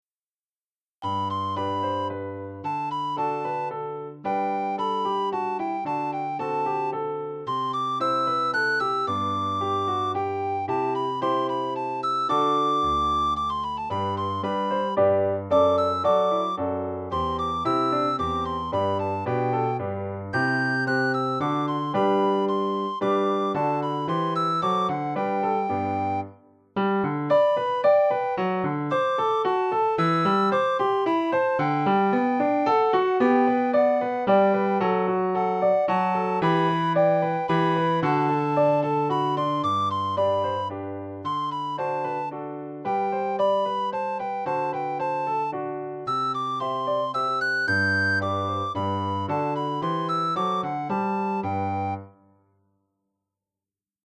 für Sopranblockflöte (Violine, Flöte) und Klavier